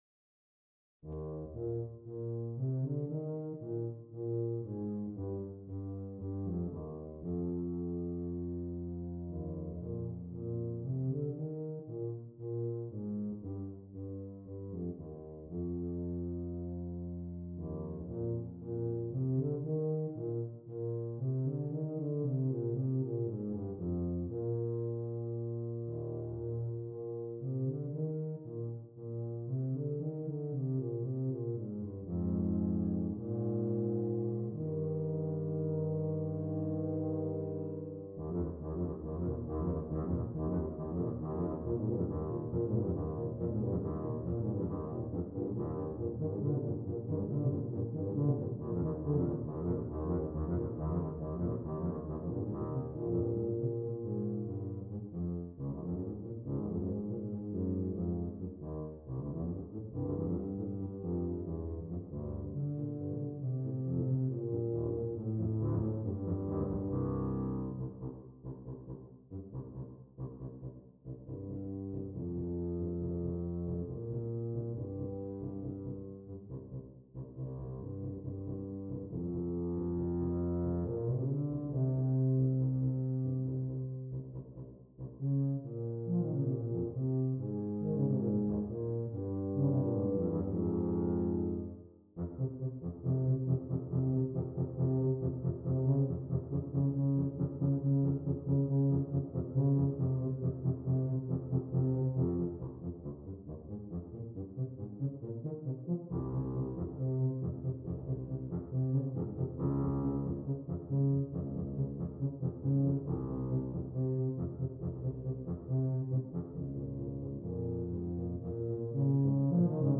2 Tubas